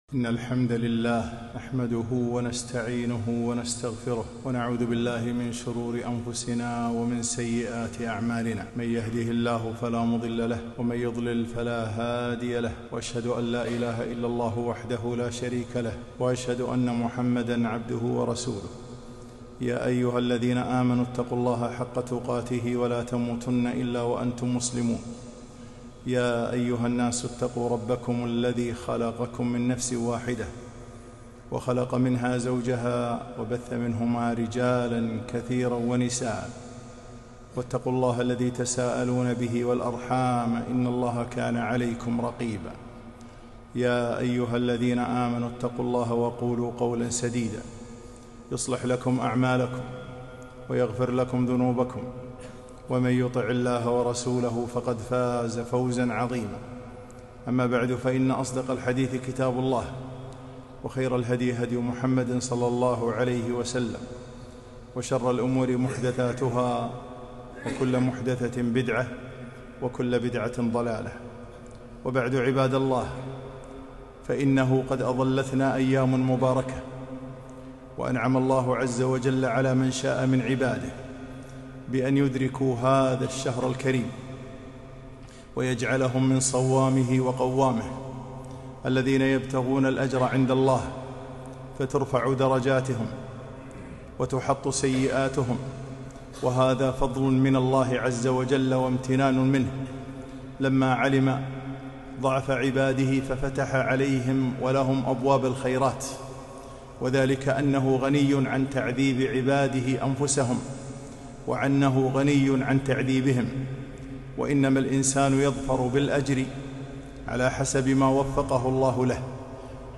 خطبة - جاءكم رمضان